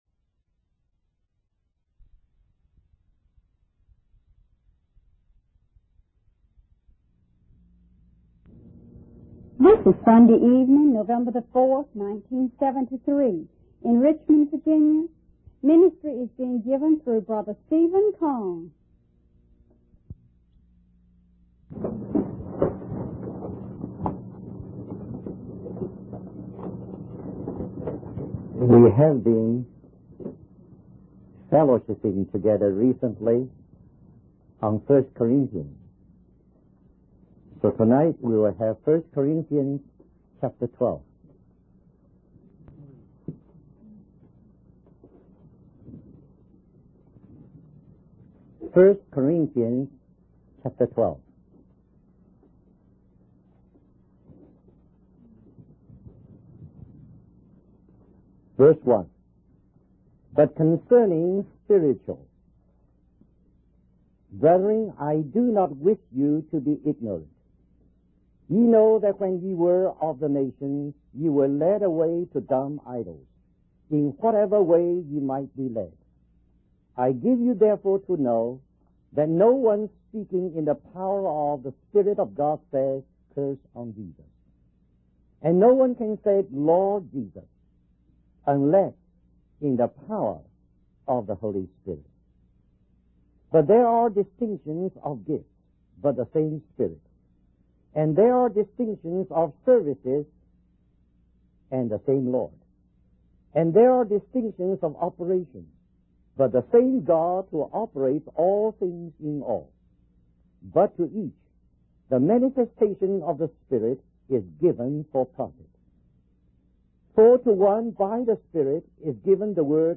In this sermon, Peter and the eleven disciples are explaining the outpouring of the Holy Spirit to a crowd in the temple area.